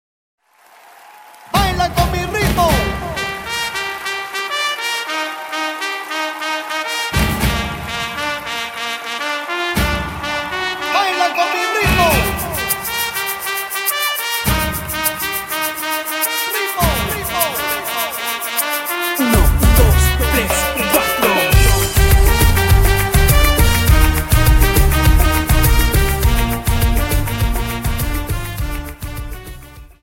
Dance: Samba 51